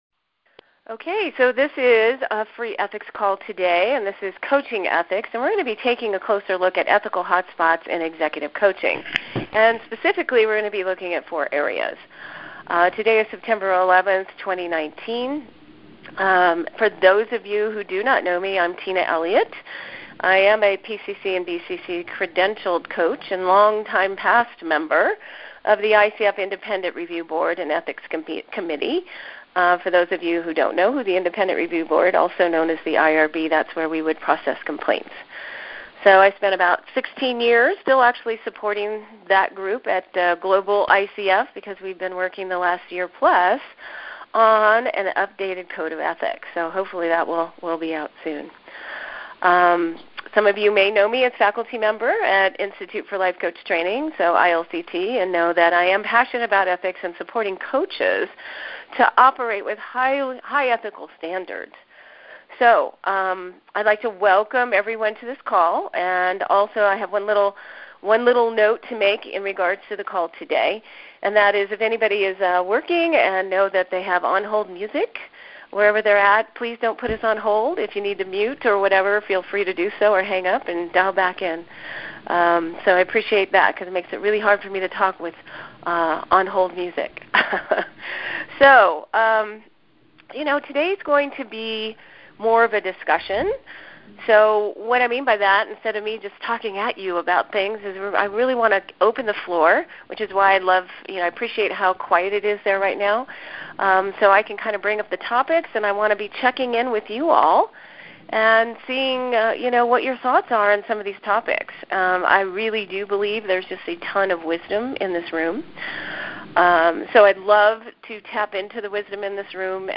Keeping it ethical with communication tools – progress reports – ROI Reports This will be an open discussion. We will be tapping into the vast array of wisdom on the call and including Q and A throughout.